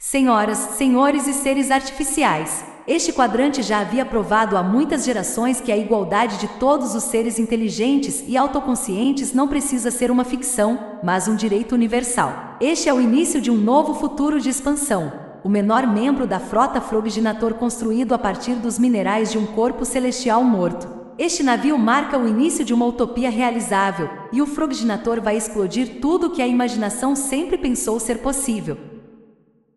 Ich habe verschiede Sprachsynthesen für die Vertonung getestet.
;) Und nicht über die Sprachgeschwindigkeit wundern, denn FrogBots sind das gewohnt.
Test - MS-Edge: